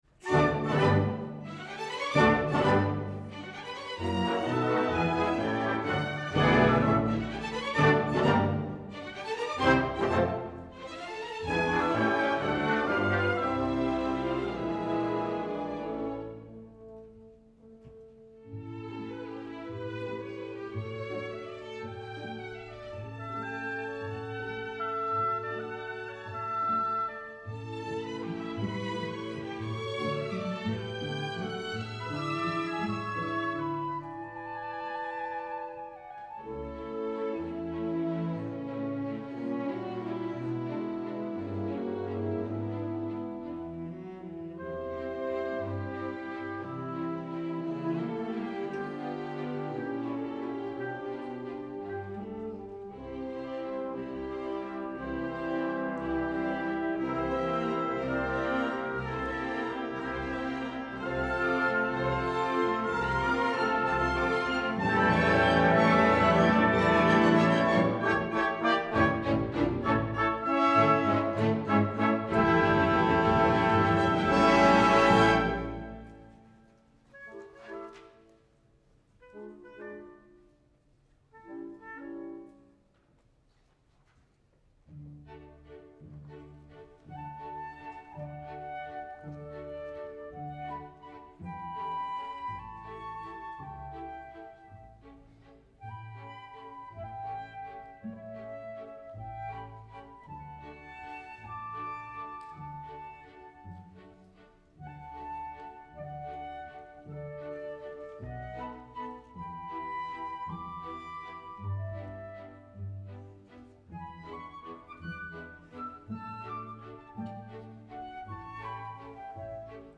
Ouvertüre
Orchester des Staatstheaters am Gärtnerplatz